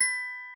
glock_B_5_2.ogg